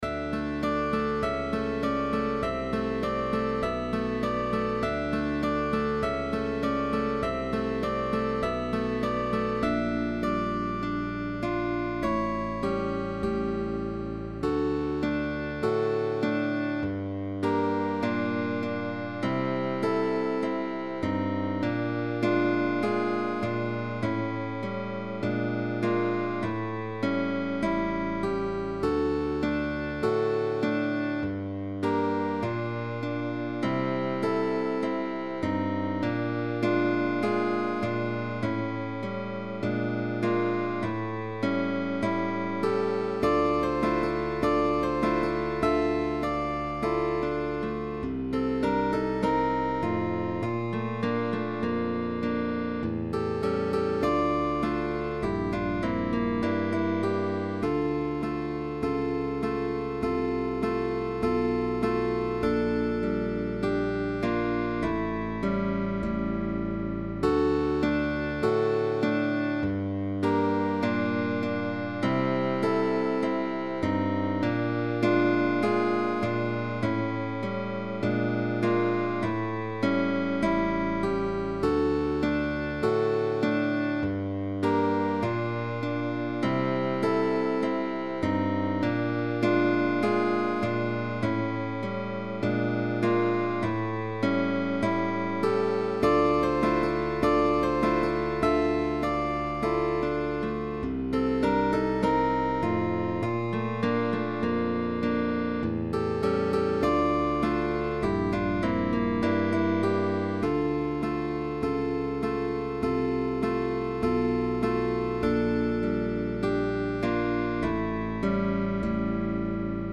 With bass optional.